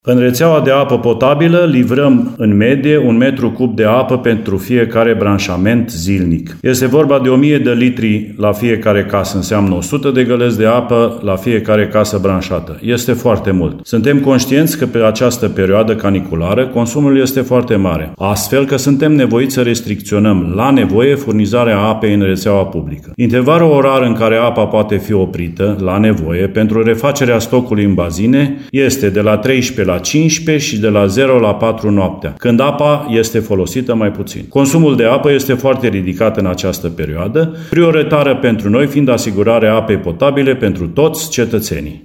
Primarul Ilie Suciu spune că întreruperile ar putea fi de câteva ore, atât ziua, cât și noaptea.